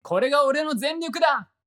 戦闘 バトル ボイス 声素材 – Battle Voice